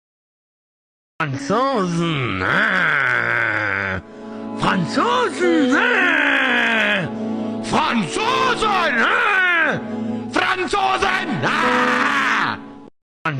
franzosen grrr Meme Sound Effect